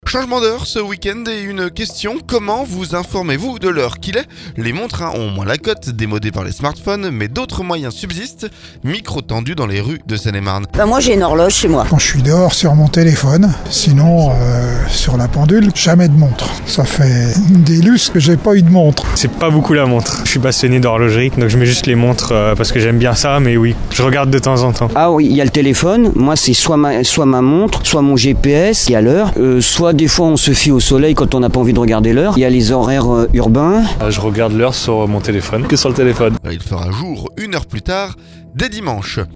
Micro tendu dans les rues de Seine-et-Marne.